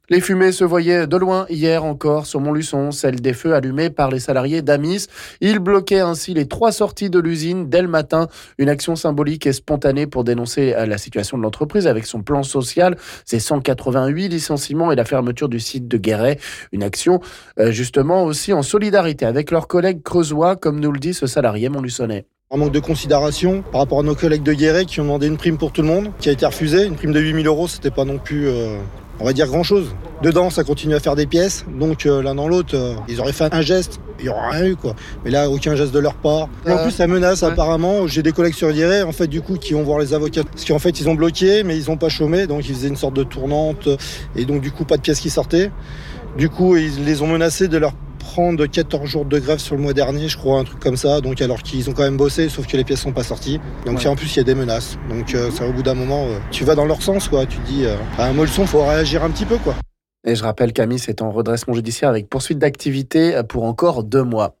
On écoute un salarié montluçonnais...